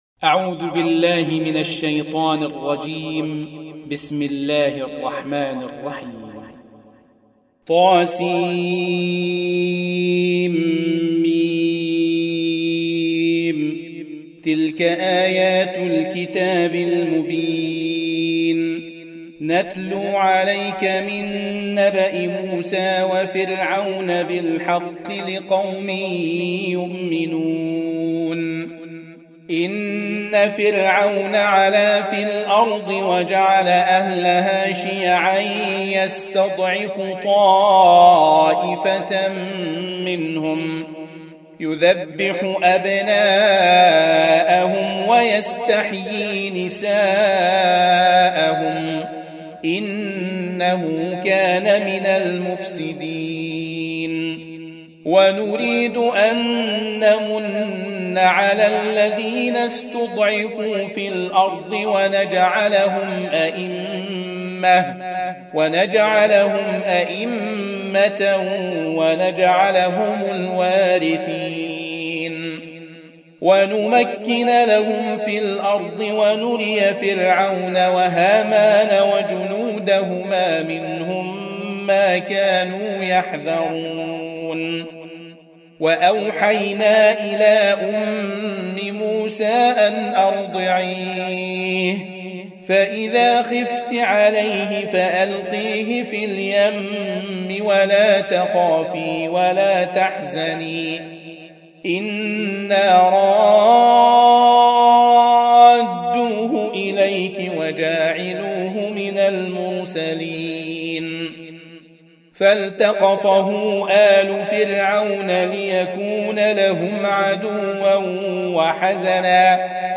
Surah Repeating تكرار السورة Download Surah حمّل السورة Reciting Murattalah Audio for 28. Surah Al-Qasas سورة القصص N.B *Surah Includes Al-Basmalah Reciters Sequents تتابع التلاوات Reciters Repeats تكرار التلاوات